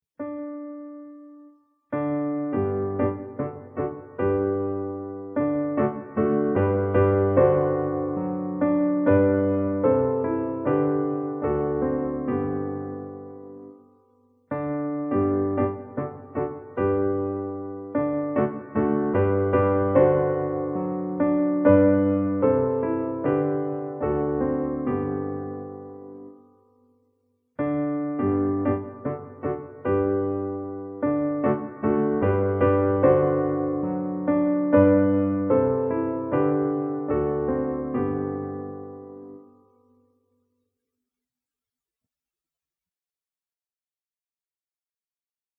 Zongorán